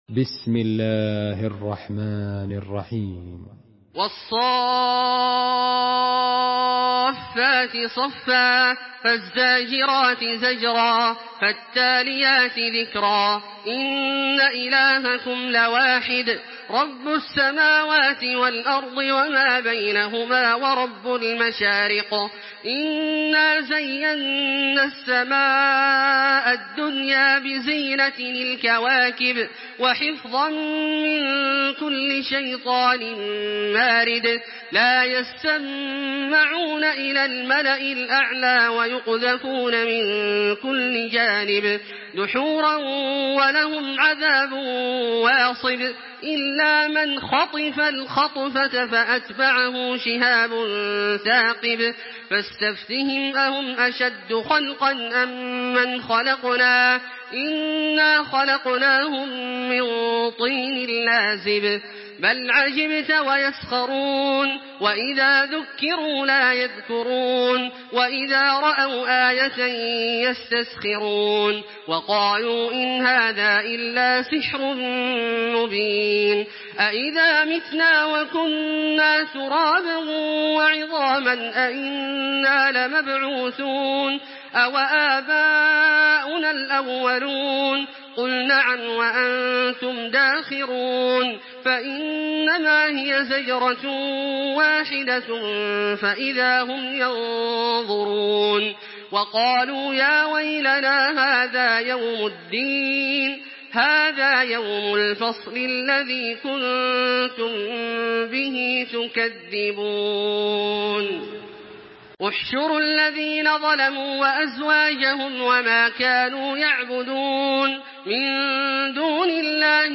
Surah Saffet MP3 by Makkah Taraweeh 1426 in Hafs An Asim narration.
Murattal